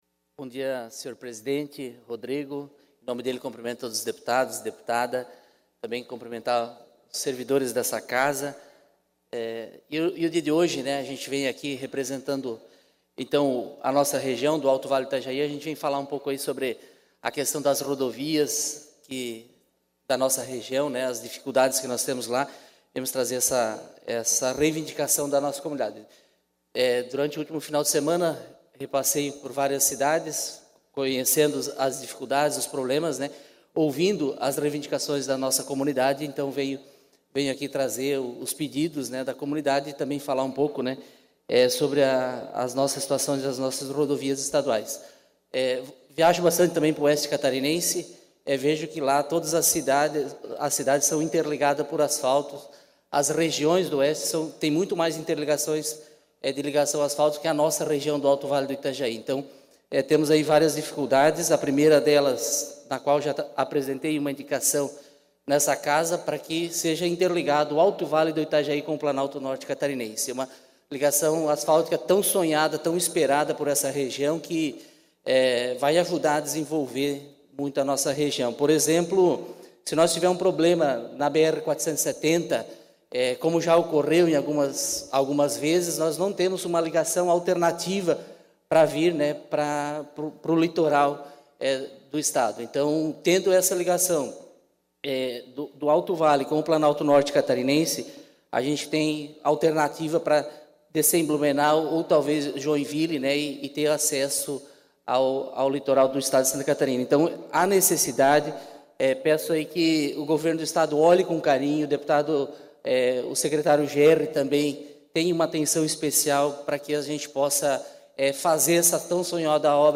Pronunciamento da sessão ordinária desta quarta-feira (29) - Manhã